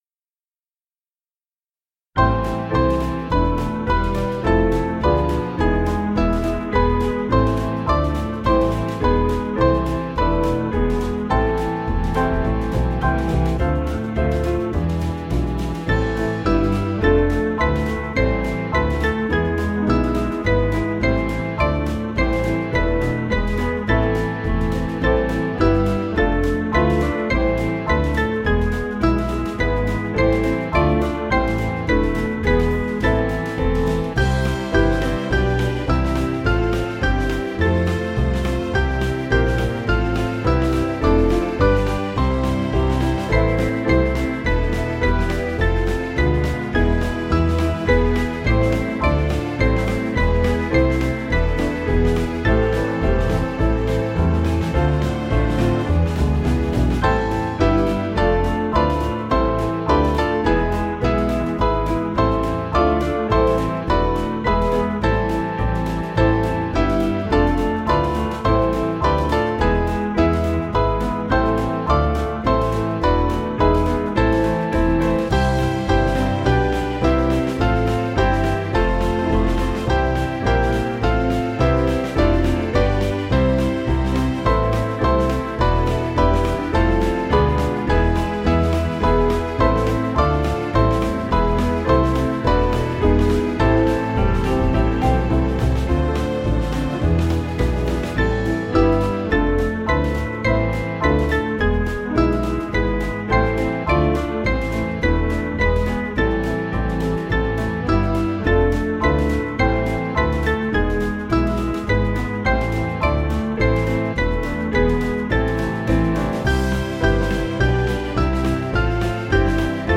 Small Band
(CM)   5/Am 481.2kb